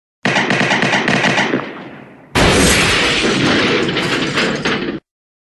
Звуки выстрела, мультфильмов
На этой странице собраны звуки выстрелов из популярных мультфильмов: пистолеты, ружья, лазерные пушки и другие забавные эффекты.
Стрельба